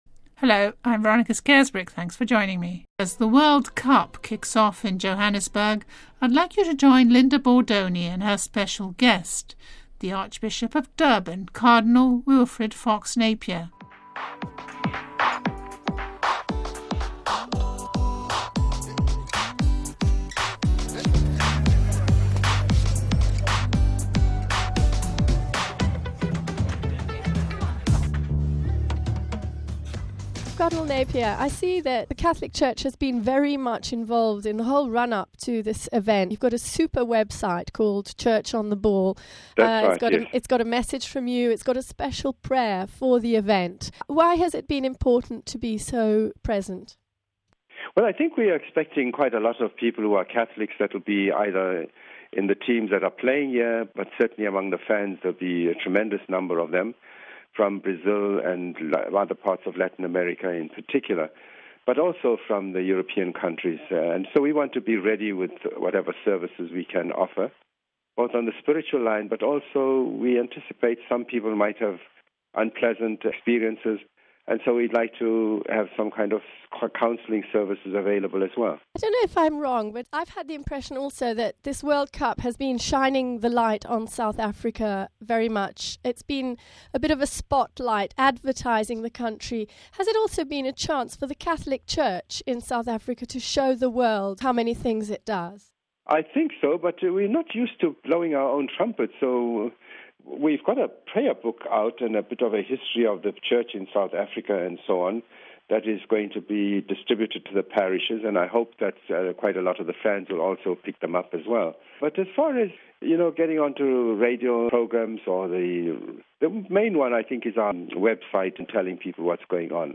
Home Archivio 2010-06-11 16:29:32 CHURCH ON THE BALL As the World Cup kicks off in Johannesburg we speak to South African Cardinal Wilfrid Fox Napier OFM.